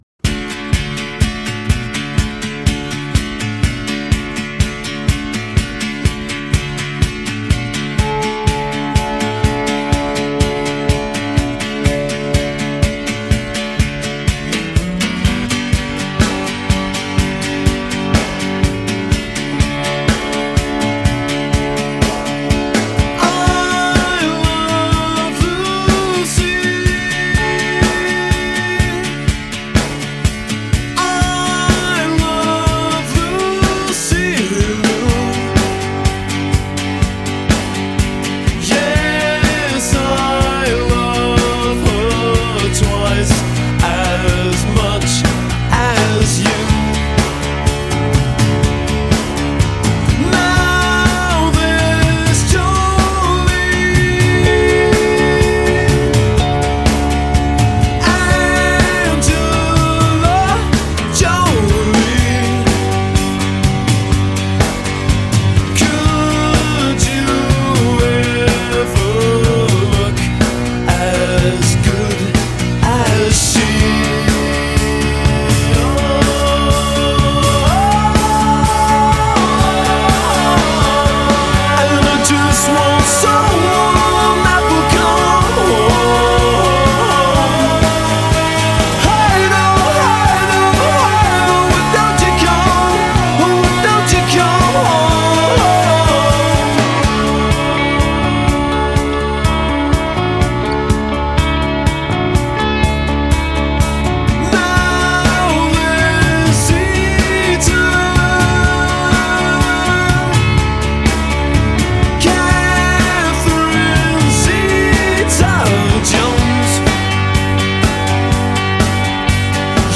updating 80’s new wave